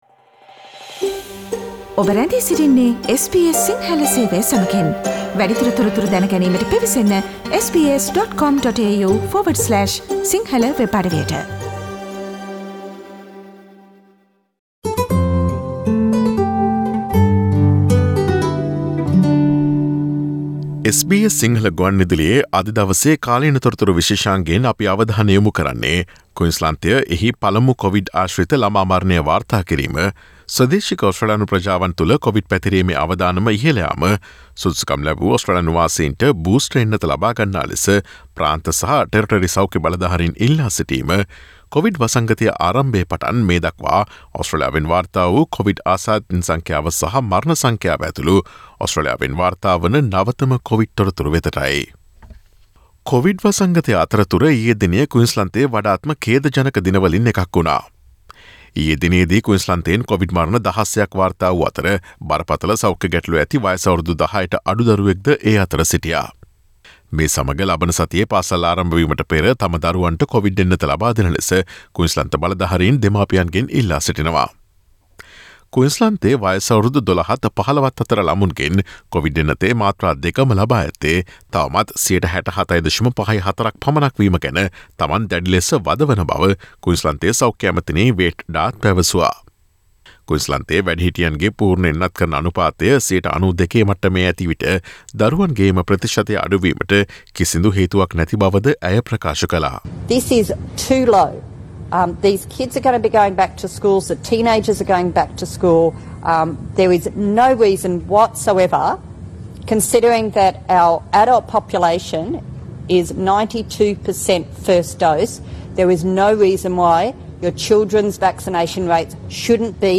සුදුසුකම් ලැබූ ඔස්ට්‍රේලියානුවාසීන්ට බූස්ටර් එන්නත ලබාගන්නා ලෙස ප්‍රාන්ත සහ ටෙරිටරි සෞඛ්‍ය බලධාරීන් ඉල්ලා සිටීම සහ කොවිඩ් වසංගතය ආරම්භයේ පටන් මේ දක්වා ඔස්ට්‍රේලියාවෙන් වාර්තා වූ කොවිඩ් ආසාදිතයින් සංඛ්‍යාව සහ මරණ සංඛ්‍යාව ඇතුළුව ඔස්ට්‍රේලියාවේ නවතම කොවිඩ් තතු විත්ති රැගත් පෙබරවාරි 03 වන දා බ්‍රහස්පතින්දා ප්‍රචාරය වූ SBS සිංහල සේවයේ කාලීන තොරතුරු විශේෂාංගයට සවන්දෙන්න.